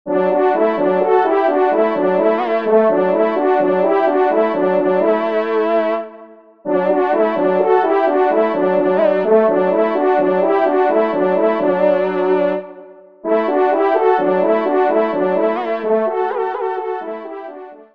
Duo Trompes     (Ton de vénerie)